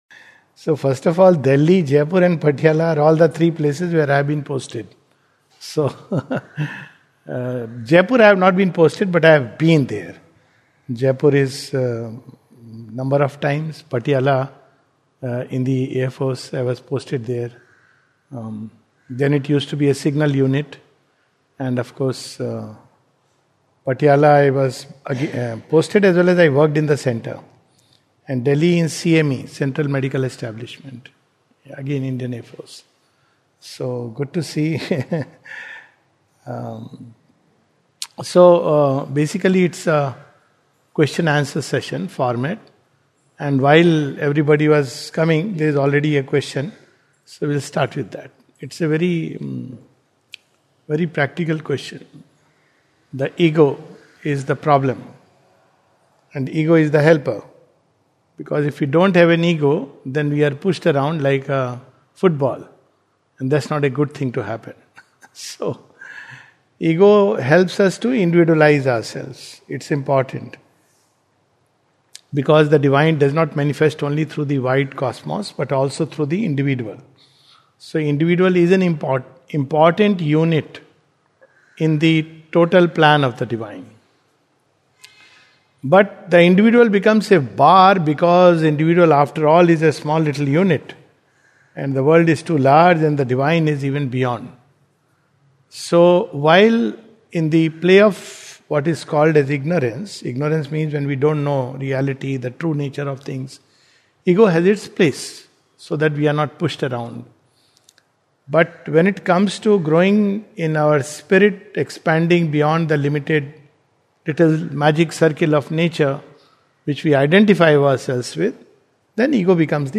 Q&A Session